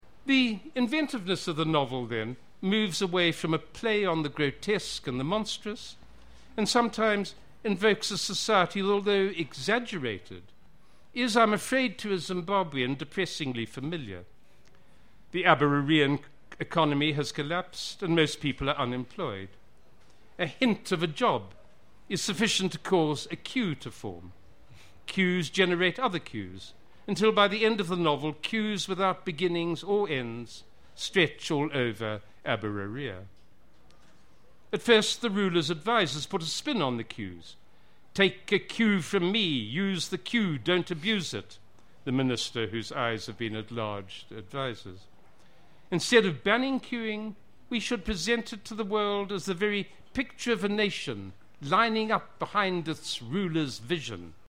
Satire - Book Café discussion
at a discussion on satire at Harare's Book Café on Thursday 27 November